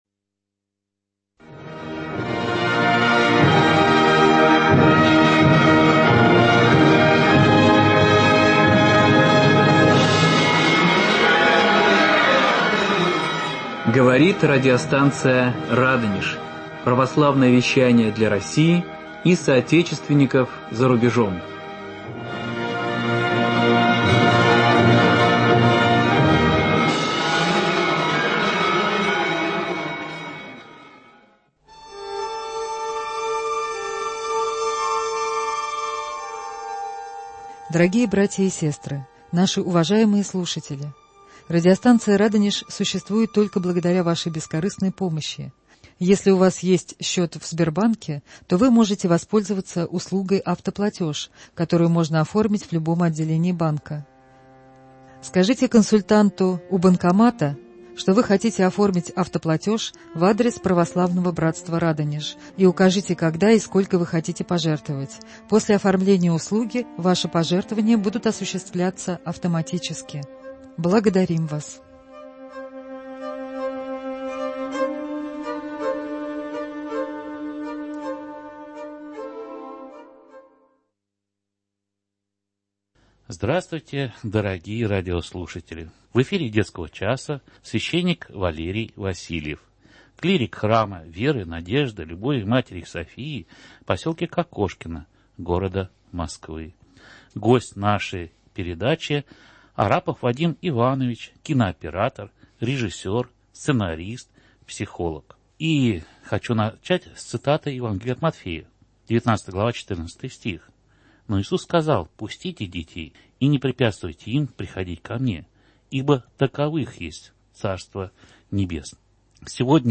Программа Детский Час - радиоспектакль